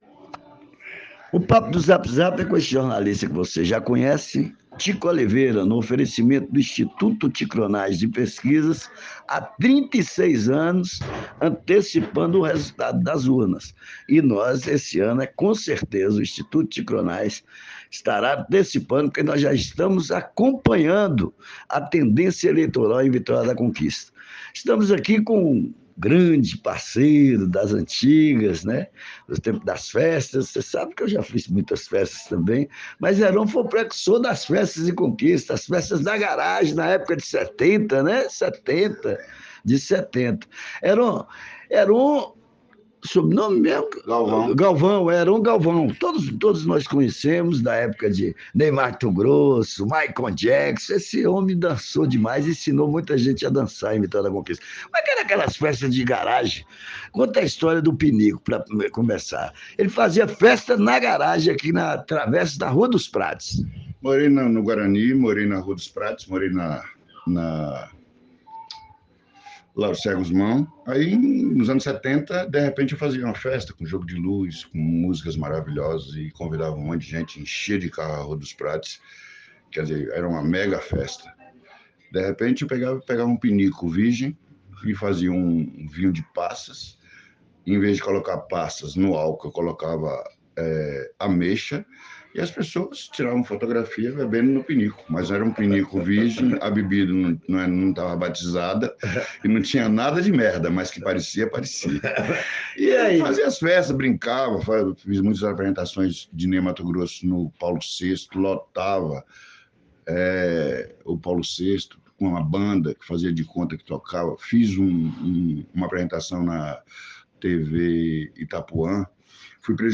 papo do zap zap uma troca de ideia impactante